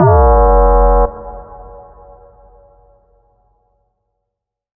BWB UPGRADE3 FX FALL (4).wav